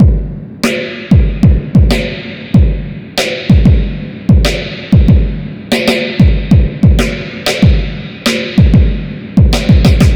Track 13 - Drum Break 06.wav